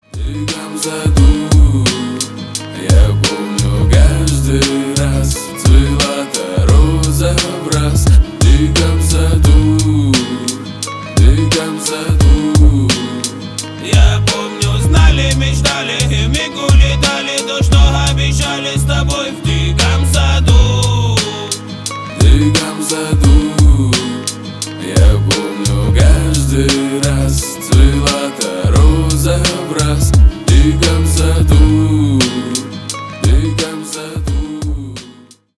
рэп , хип хоп